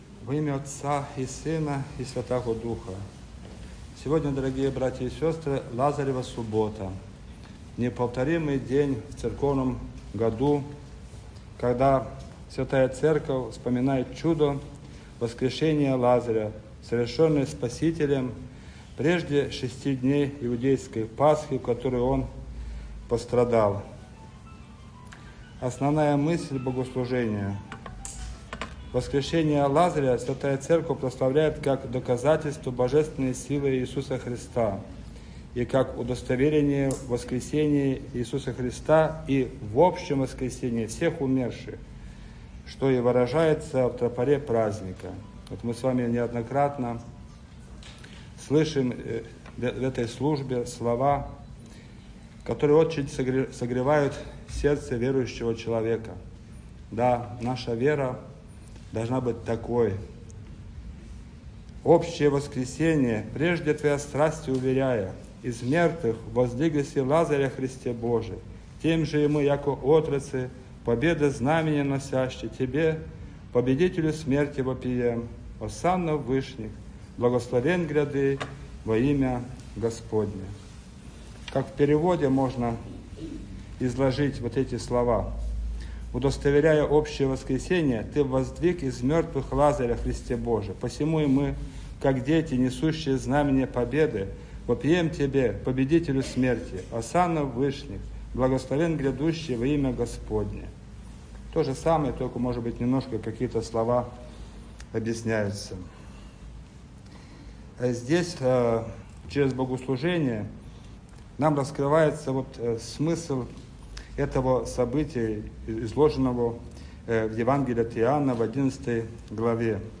Проповедь
после Божественной Литургии Лазаревой субботы: